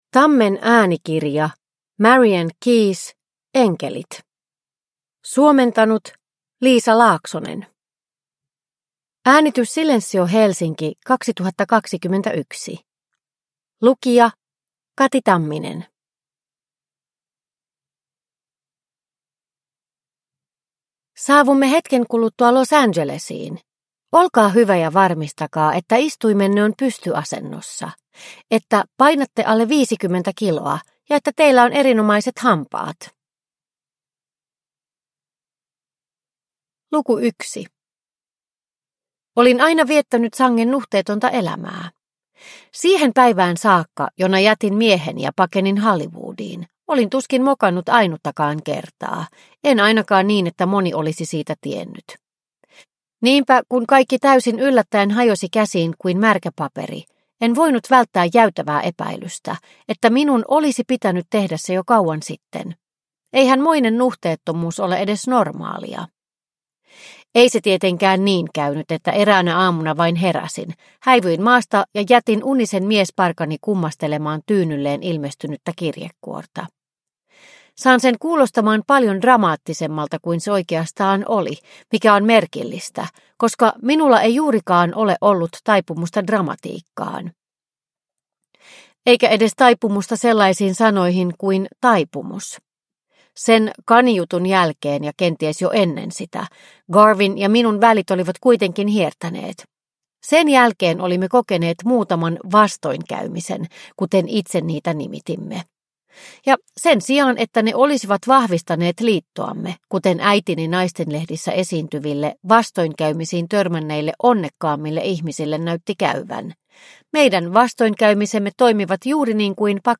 Enkelit – Ljudbok – Laddas ner